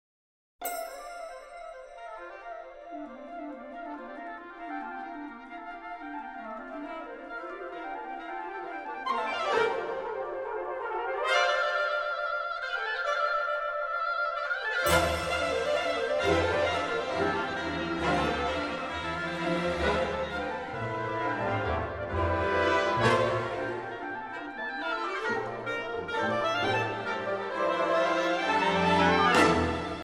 Moderately Fast 17:15